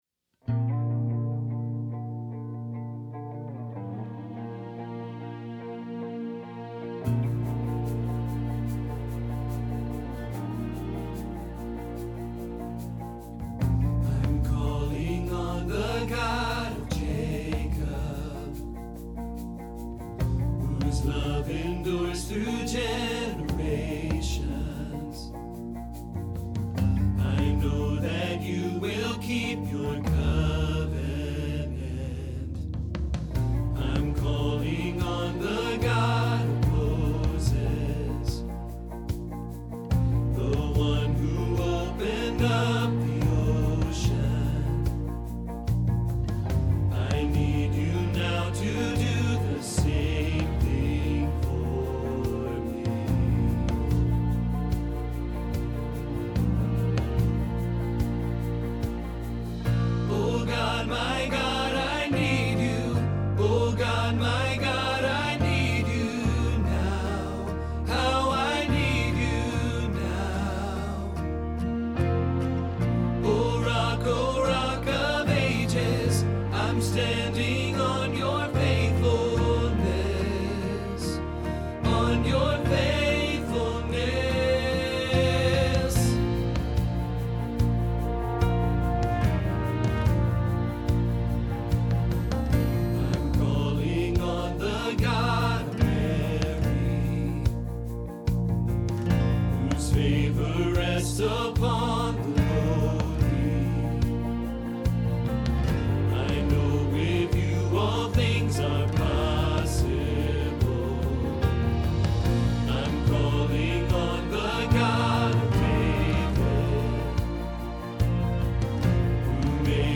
Same-God-Tenor.mp3